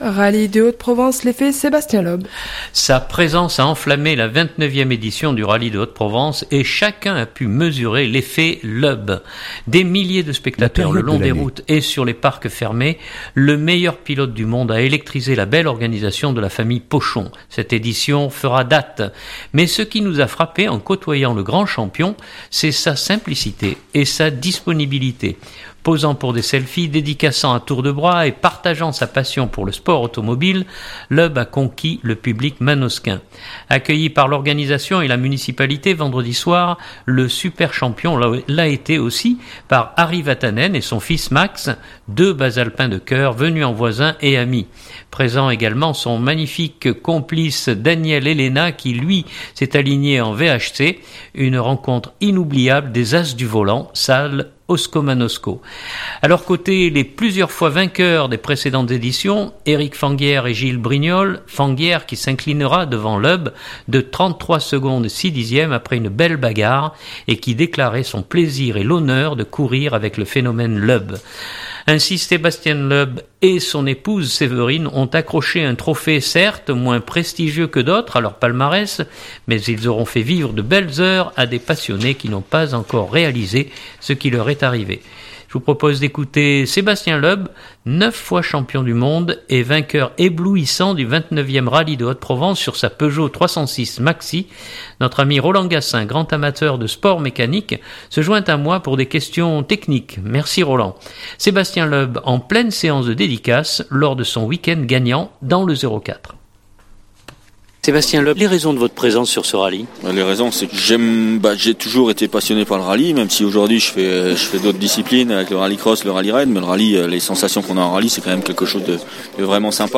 Je vous propose d’écouter Sébastien Loeb, neuf fois champion du monde et vainqueur éblouissant du 29ème Rallye de Haute-Provence sur sa Peugeot 306 maxi.